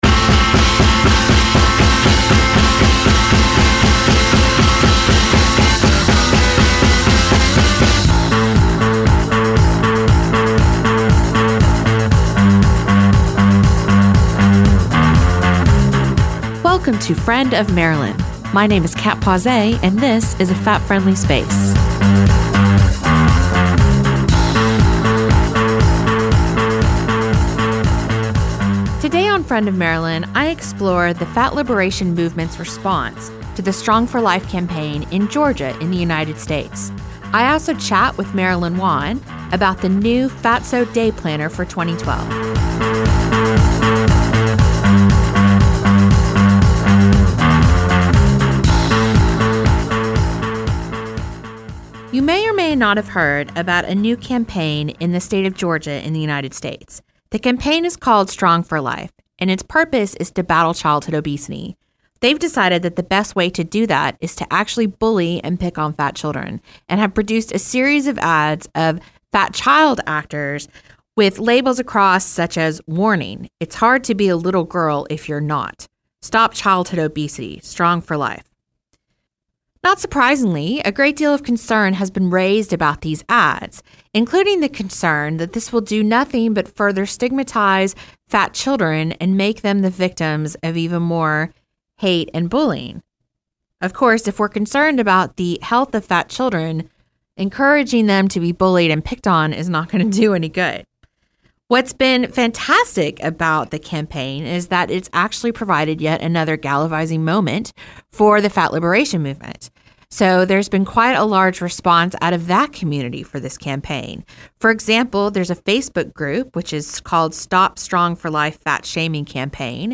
access radio